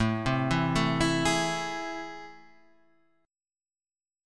AmM7 chord